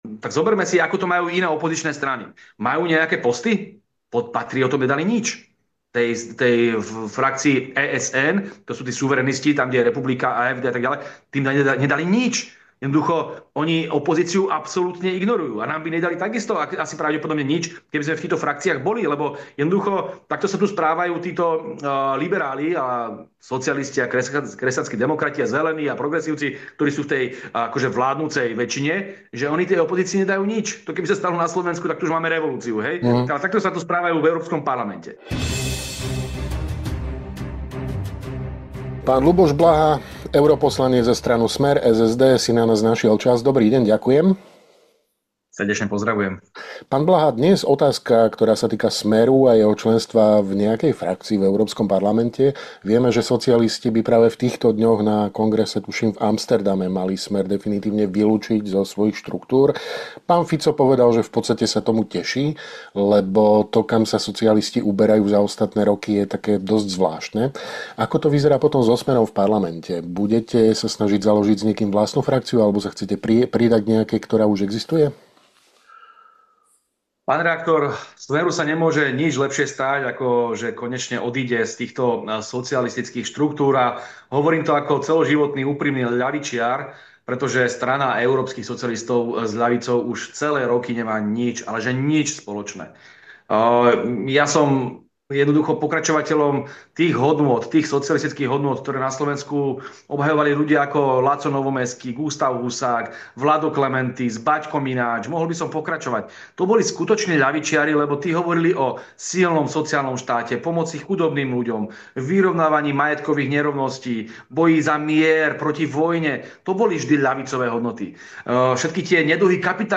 V rozhovore pre Hlavné správy opisuje odklon európskych socialistov od tradičných hodnôt sociálnej spravodlivosti, keď namiesto pomoci chudobným riešia progresívne témy ako LGBTI agenda či Green Deal.